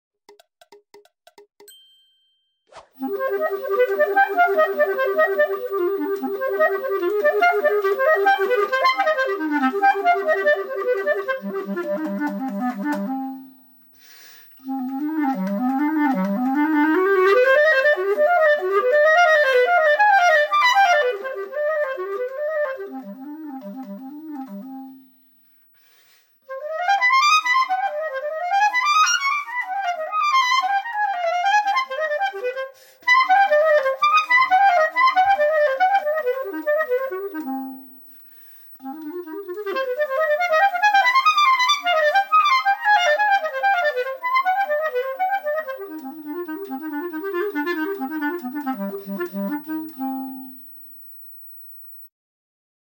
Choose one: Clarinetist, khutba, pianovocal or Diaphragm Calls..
Clarinetist